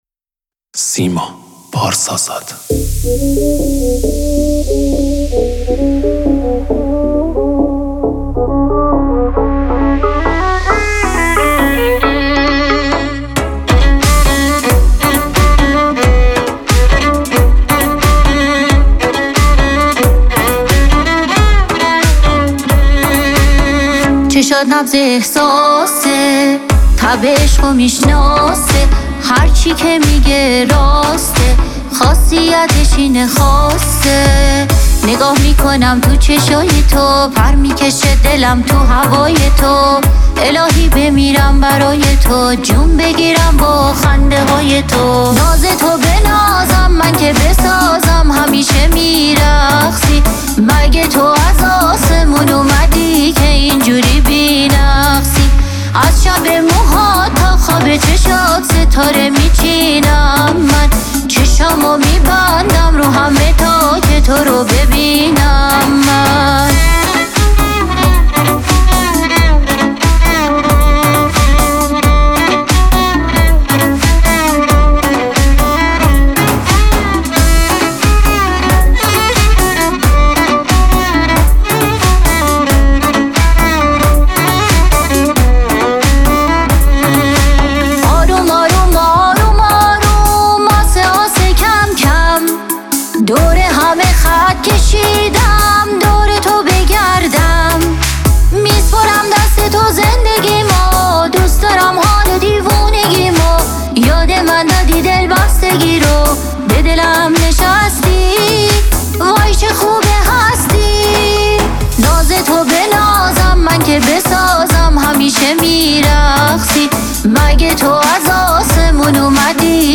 ترانه سرا و خواننده ایرانی
پاپ